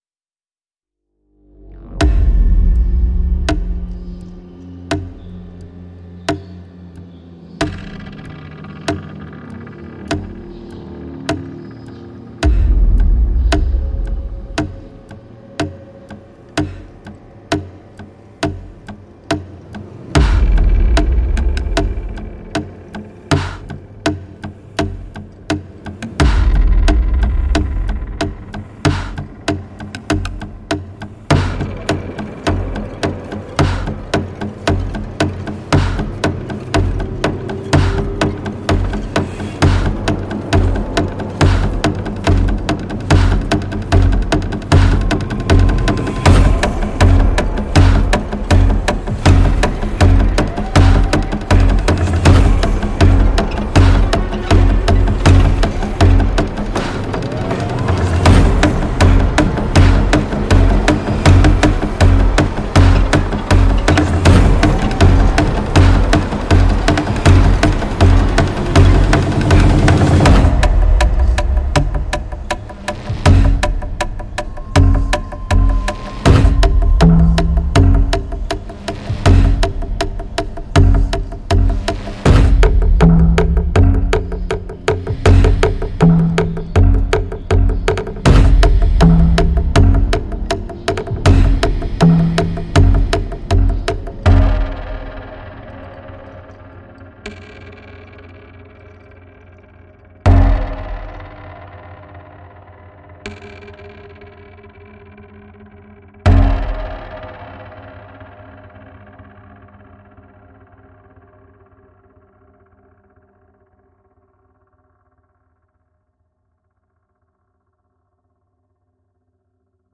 顾名思义，就是鲸鱼的骨头通过敲击采样制作的一款KONTAKT打击音源。